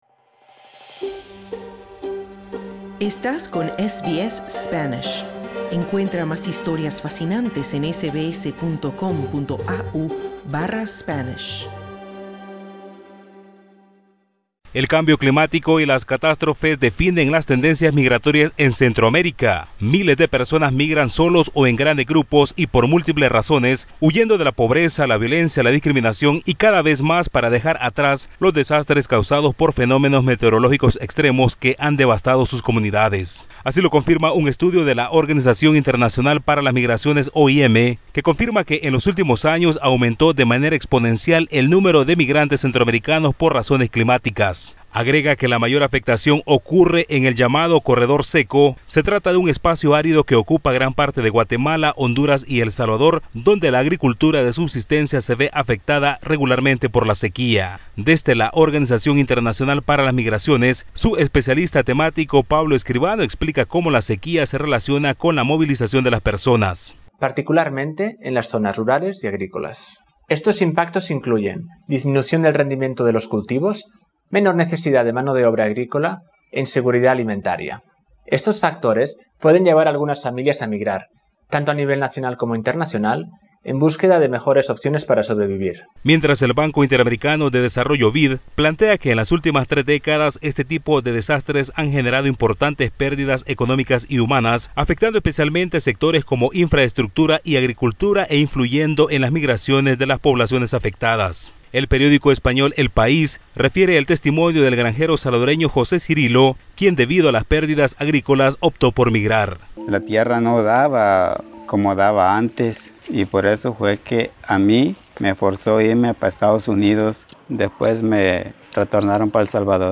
Un organismo de las Naciones Unidas, confirma que cada vez más centroamericanos buscan emigrar para dejar atrás los desastres causados por fenómenos meteorológicos extremos. Escucha el informe desde Centroamérica